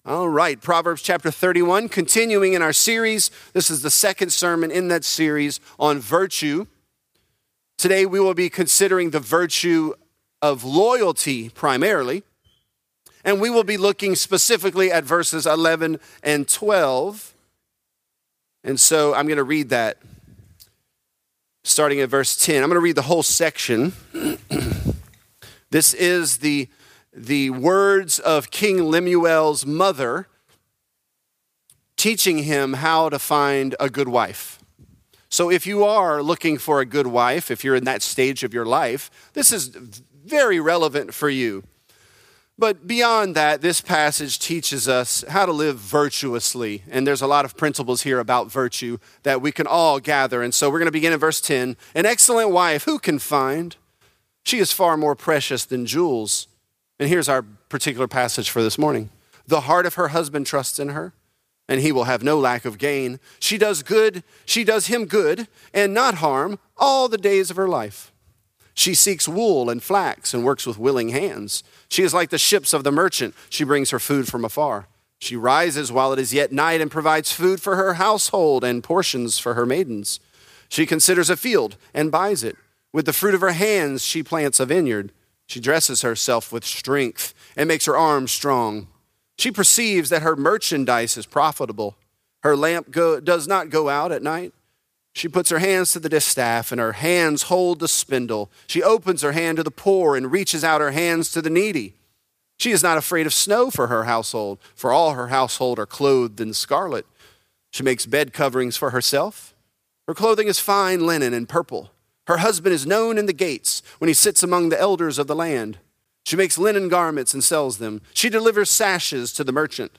Virtuous: Her Husband Trusts Her | Lafayette - Sermon (Proverbs 31)